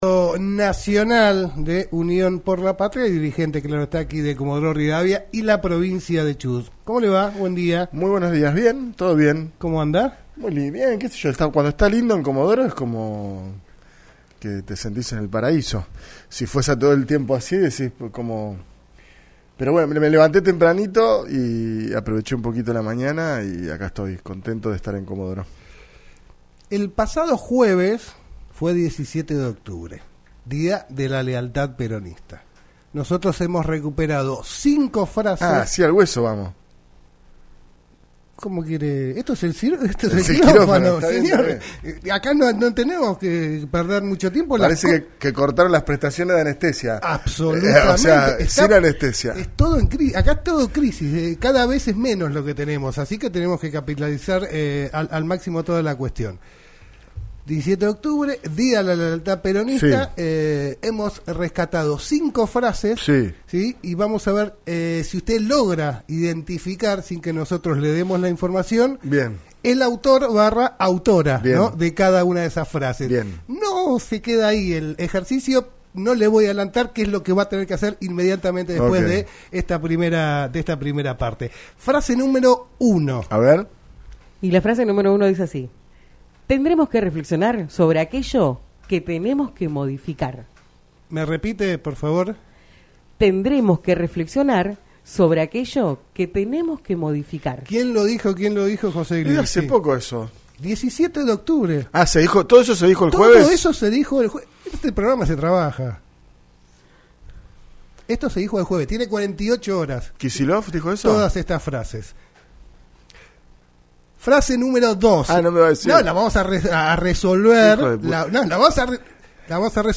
José Glinski, diputado nacional de Unión por la Patria, visitó los estudios de LaCienPuntoUno para hablar en "El Quirófano" sobre la interna del peronismo a nivel nacional, la Boleta Única de Papel y lo que viene siendo la gestión de Nacho Torres como gobernador de la provincia de Chubut.